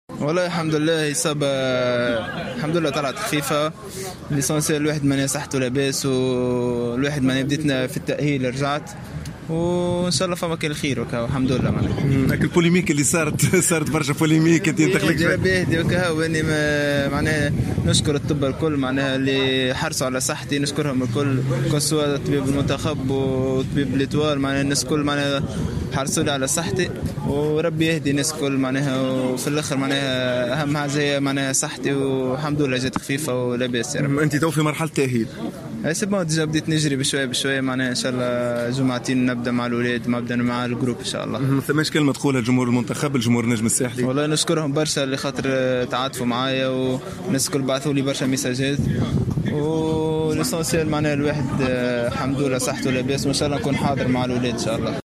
أكد لاعب النجم الرياضي الساحلي والمنتخب الوطني في تصريح خاص بالجوهرة أف أم أنه بصدد مواصلة التأهيل البدني بعد أن تأكد من أن إصابته لا تكتسي خطورة كبيرة.